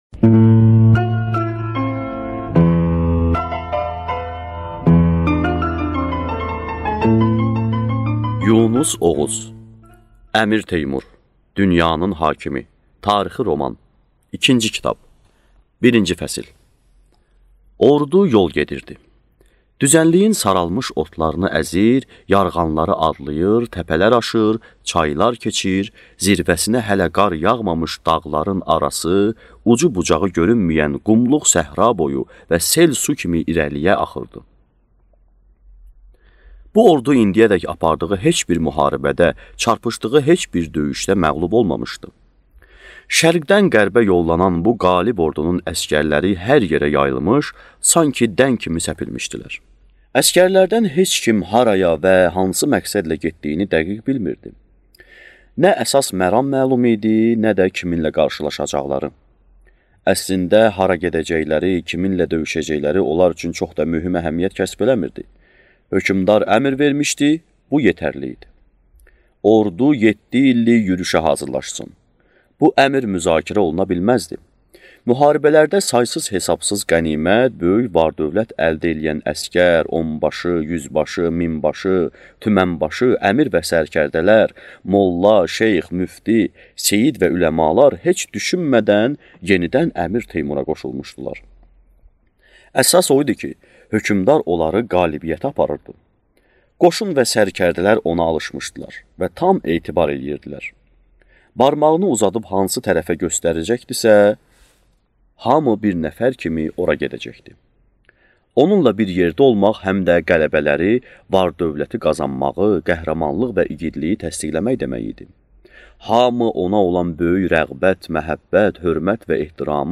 Аудиокнига Əmir Teymur dünyanın hakimi | Библиотека аудиокниг
Прослушать и бесплатно скачать фрагмент аудиокниги